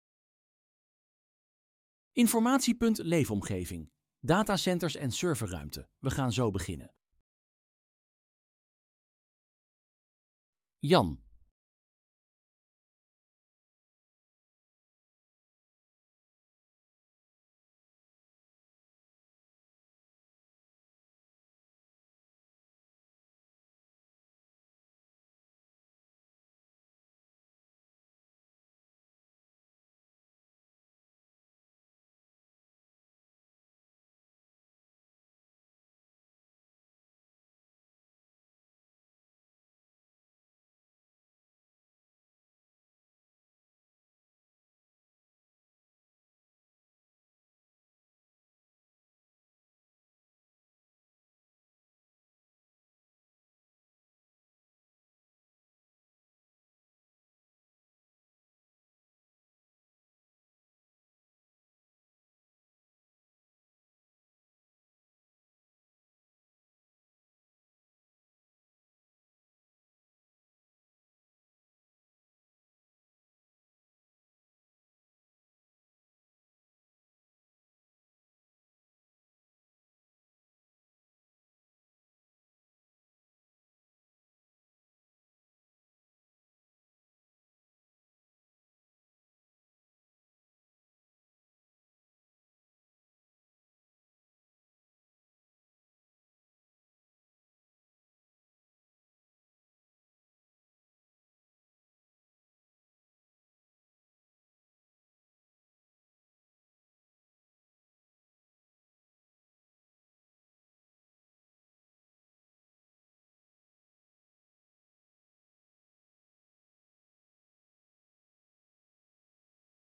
Webinar 'Verlichten en verwarmen (utiliteits)gebouwen'